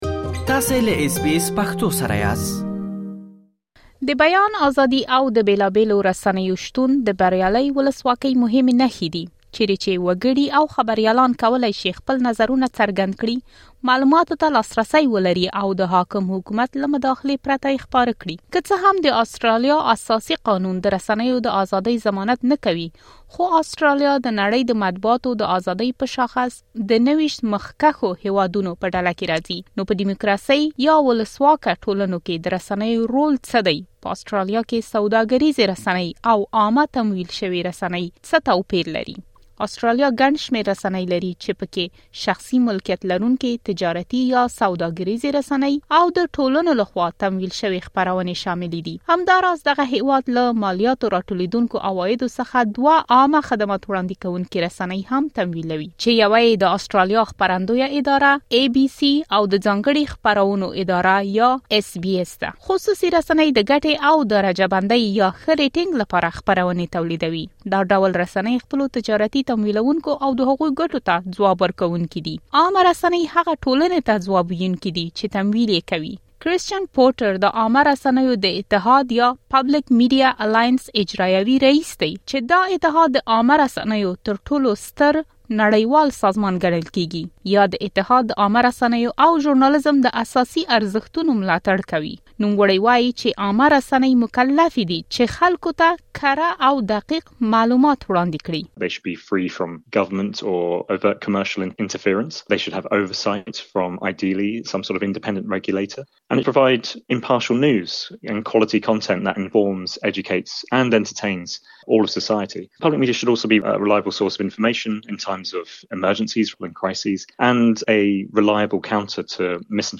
پدې راپور کې پدې خبرې کېږي چې که څه هم عامه تمویل شوې رسنۍ له حکومت څخه پیسې ترلاسه کوي، خو دا د هغو دولتي تمویل شویو رسنیو چې په نوره نړۍ کې موندل کېږي توپیر لري.